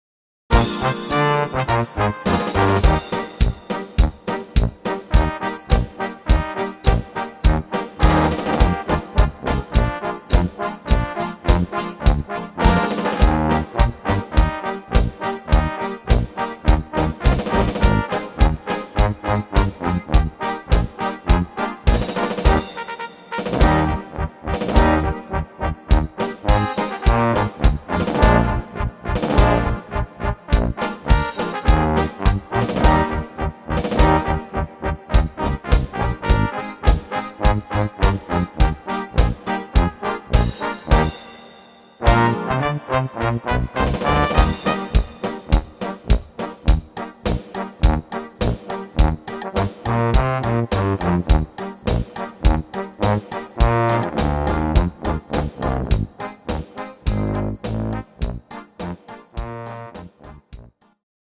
fetzig witzige Blasmusik